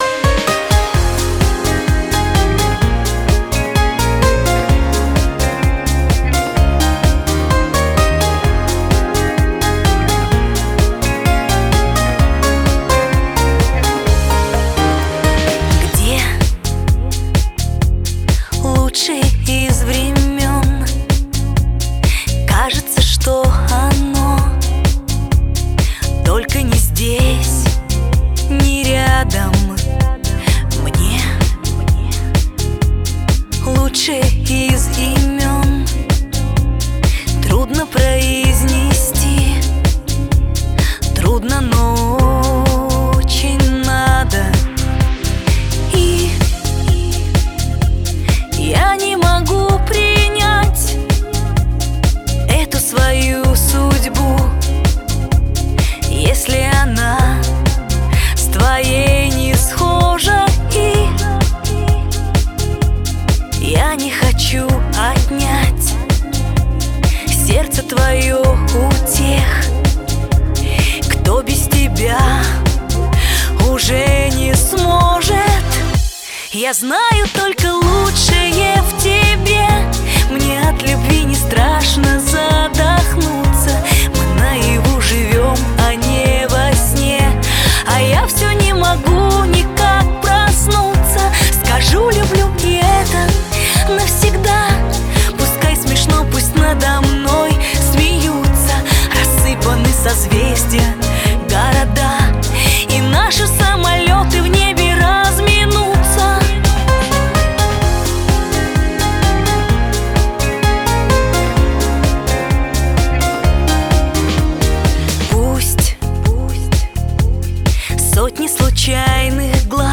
Головна » Файли » Музика » Спокійні